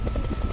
TB_propeller.ogg